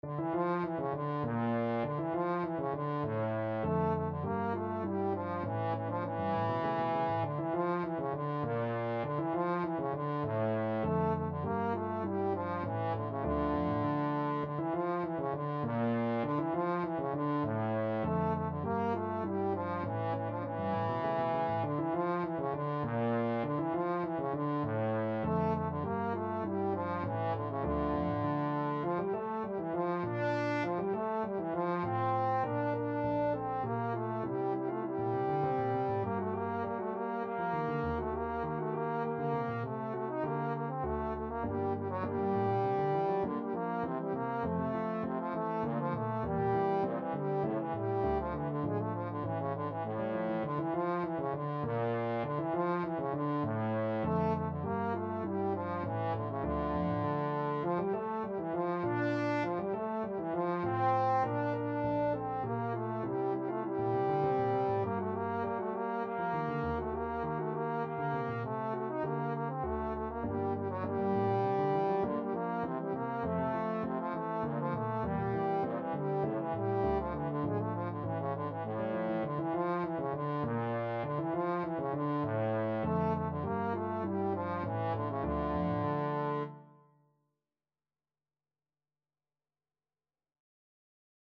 Trombone version
Allegretto = 100
3/4 (View more 3/4 Music)
Classical (View more Classical Trombone Music)